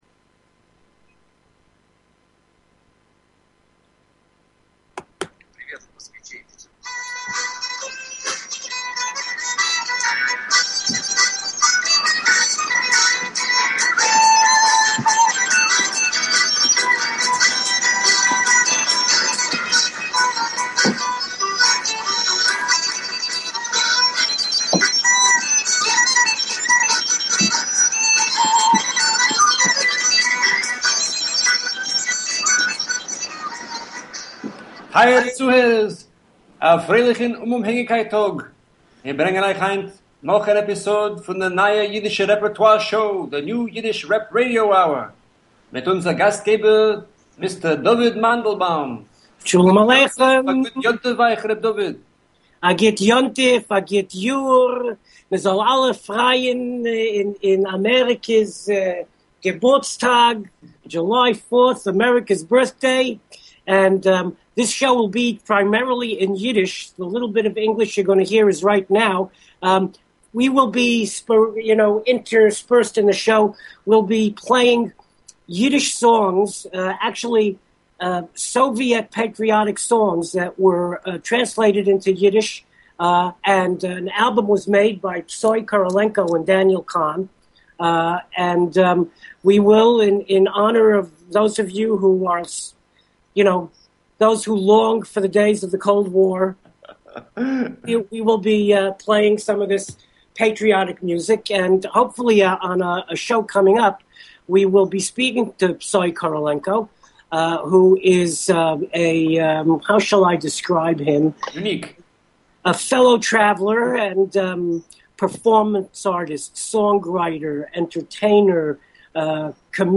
Talk Show Episode, Audio Podcast, New_Yiddish_Rep_Radio_Hour and Courtesy of BBS Radio on , show guests , about , categorized as
A forum for Yiddish Culture on internet radio. Talk radio in Yiddish, in English, sometimes a mix of both, always informative and entertaining. NYR Radio hour will bring you interviews with Yiddish artists, panel discussions, radio plays, comedy, pathos, and a bulletin board of events, as well as opinions and comments from listeners.